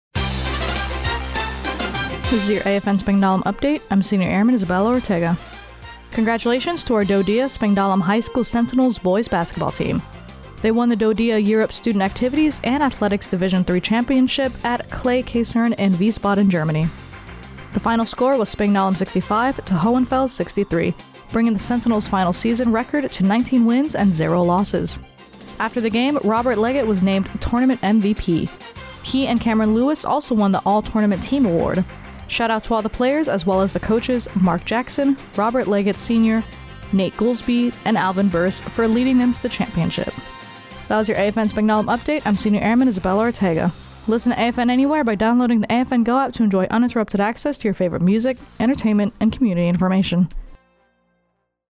Radio news.